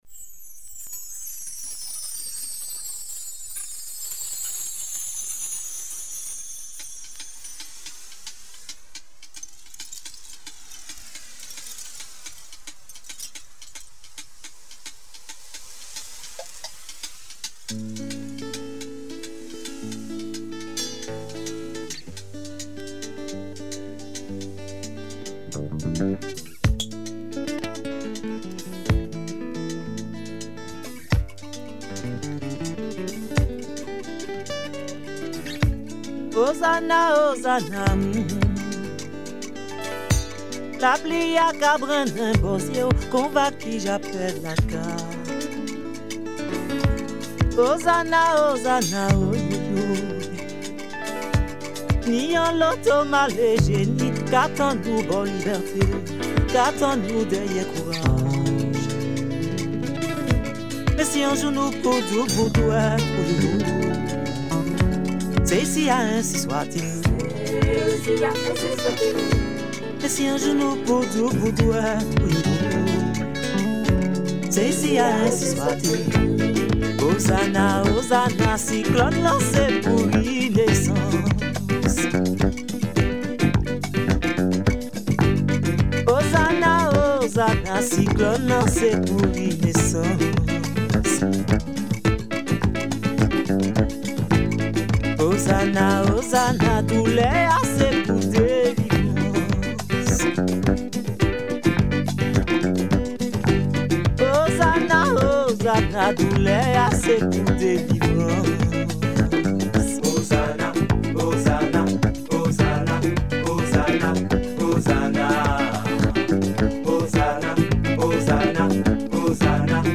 Some killer Caribbean tunes